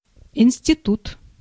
Ääntäminen
Ääntäminen US RP : IPA : /ˌɪnstɪˈtjuːʃən/ GenAm: IPA : /ˌɪnstɪˈtuːʃən/ Lyhenteet ja supistumat (laki) Inst.